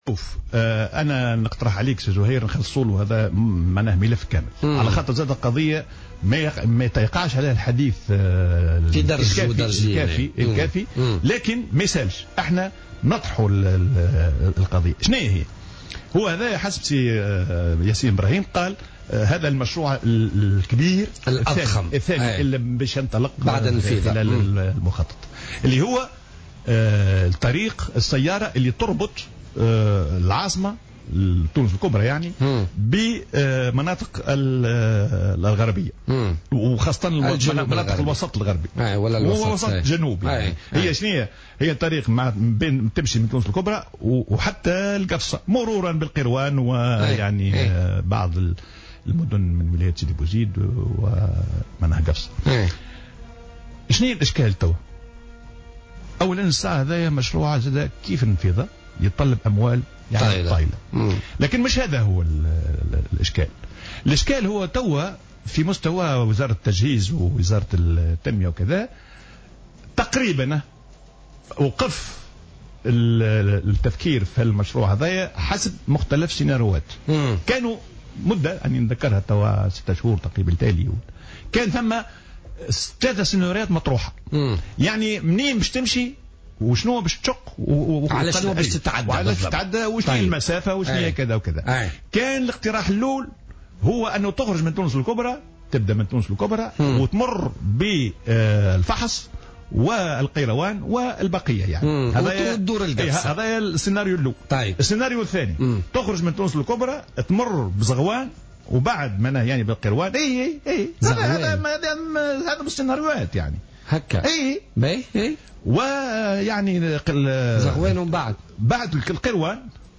وقال الديماسي، ضيف برنامج "بوليتيكا" اليوم الجمعة أن عديد المشاريع الاقتصادية والتنموية في تونس دون أهداف ولم يتم دراسة جدواها.